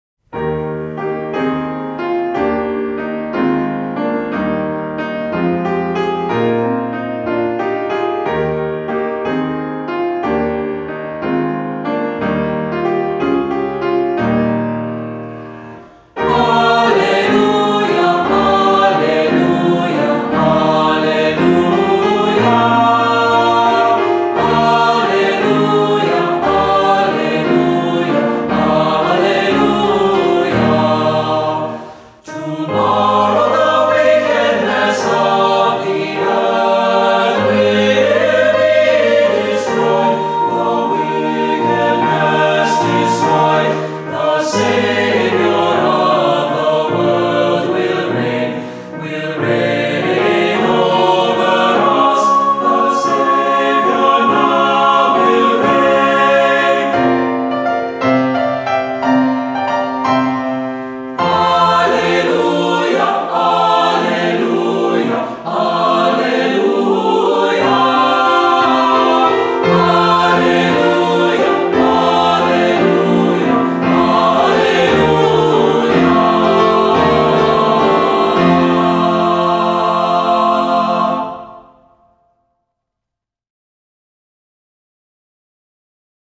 Voicing: Cantor,Assembly,SATB